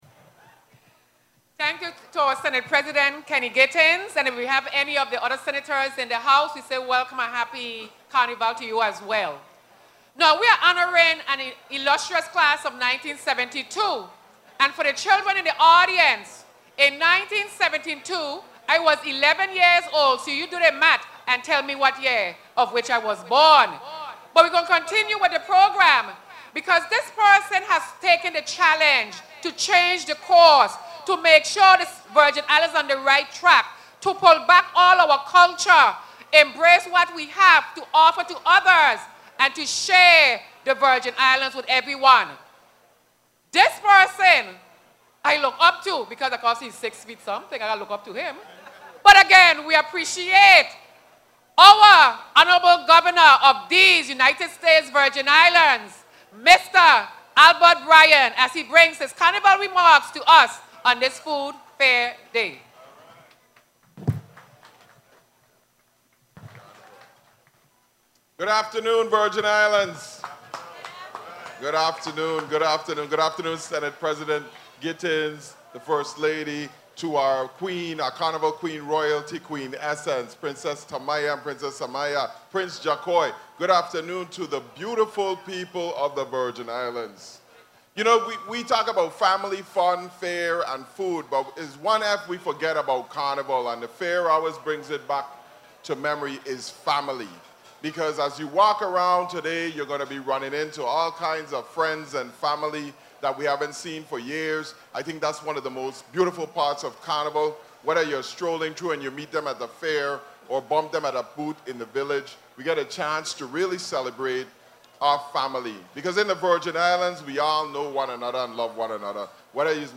CHARLOTTE AMALIE—Governor Albert Bryan Jr. and Lt. Governor Tregenza Roach opened the St. Thomas Food, Arts and Crafts Fair this Wednesday which showcased the growing variety and diversity of Virgin Islands culture.
Listen to Governor Bryan’s remarks